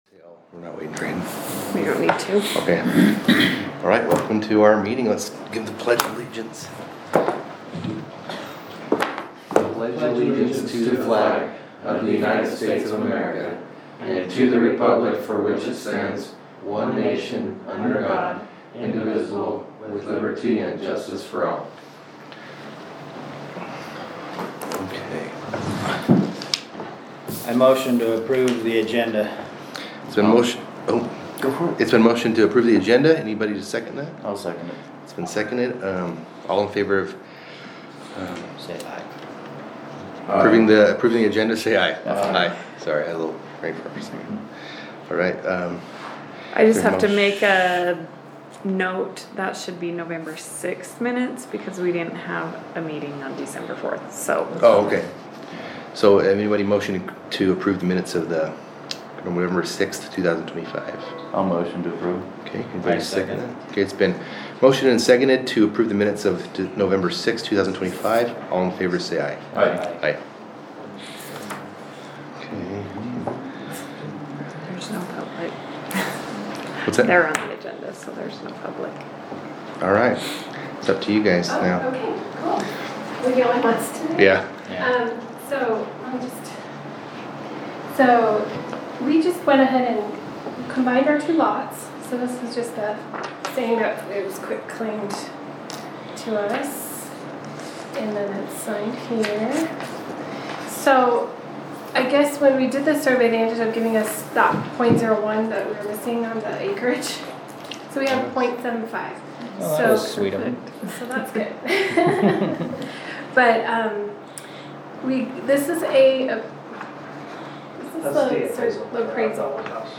Meeting
Fielding, UT 84311